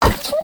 Minecraft Version Minecraft Version latest Latest Release | Latest Snapshot latest / assets / minecraft / sounds / mob / wolf / puglin / hurt3.ogg Compare With Compare With Latest Release | Latest Snapshot
hurt3.ogg